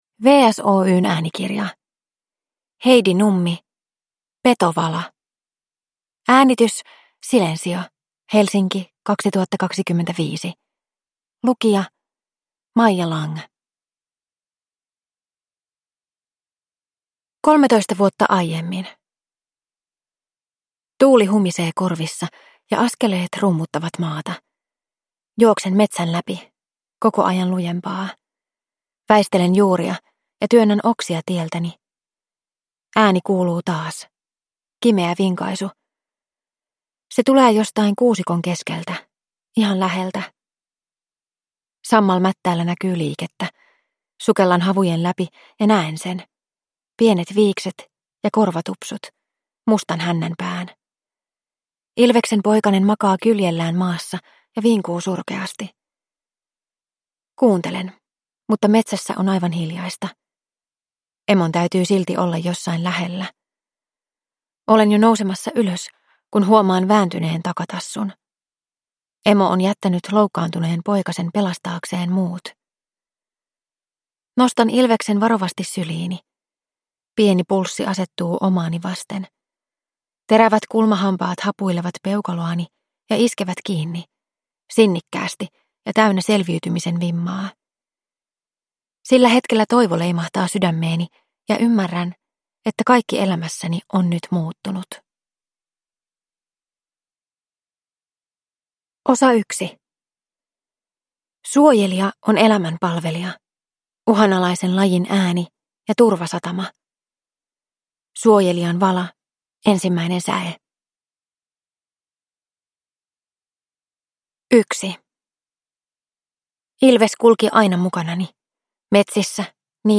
Petovala – Ljudbok